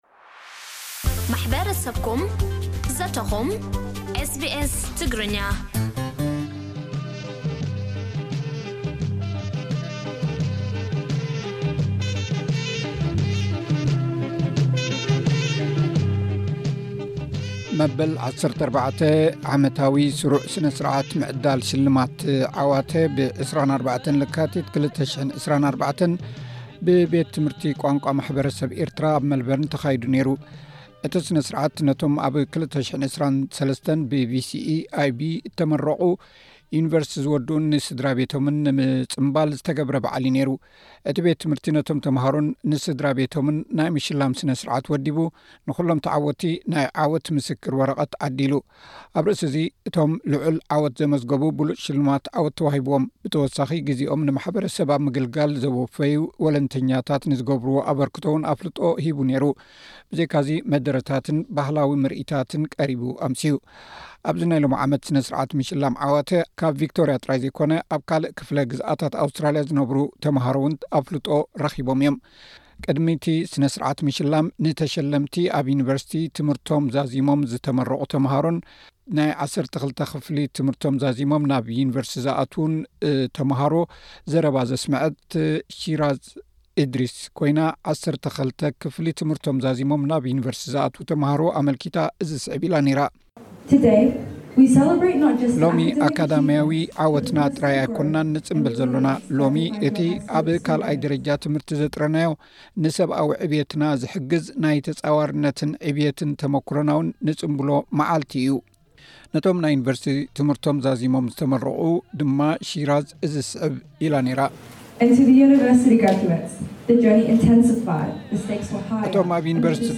ጸብጻብ፥ መበል 14 ዓመታዊ ስሩዕ ስነ-ስርዓት ምዕዳል ሽልማት ዓዋተ፡ ብ24 ለካቲት 2024 ብቤት ትምህርቲ ቋንቋ ማሕበረሰብ ኤርትራ ኣብ መልበርን ተኻይዱ ። እቲ ስነ ስርዓት ፡ ነቶም ኣብ 2023 ብ VCE, IB.